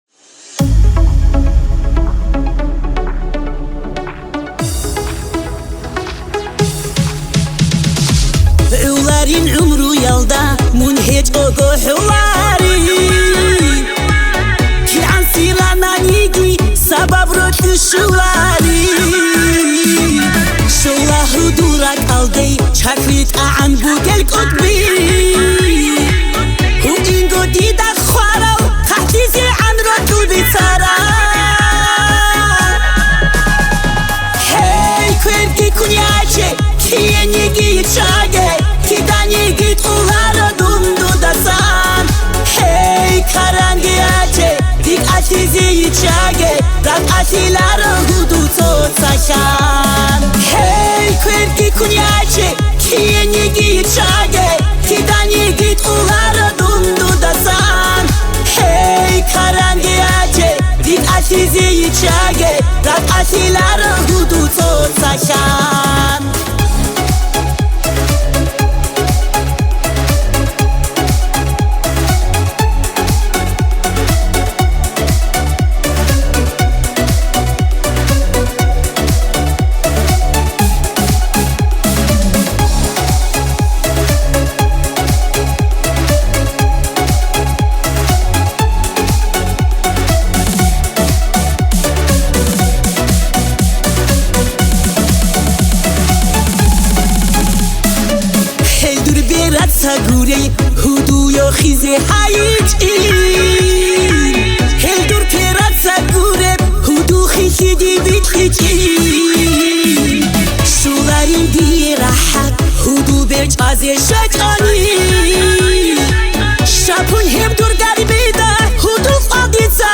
Cover version 2024